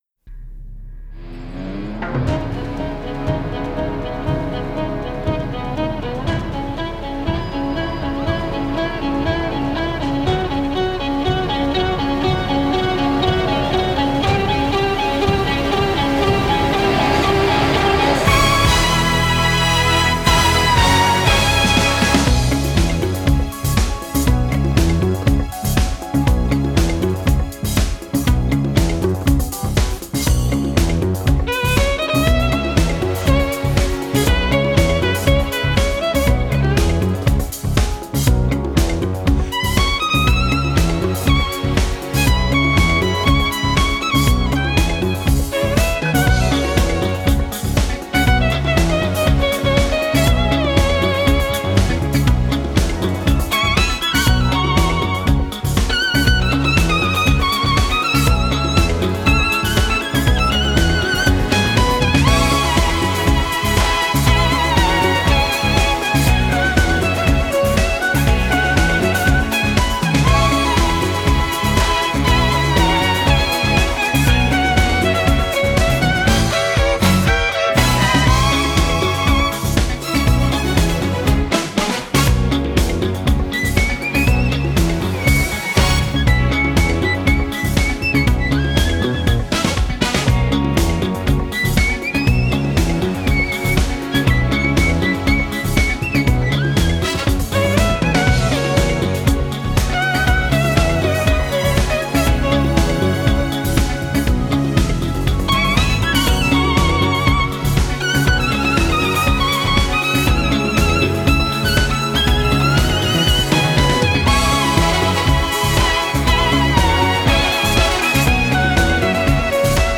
Genre: Score
virtuoses Spiel mit modernen Klängen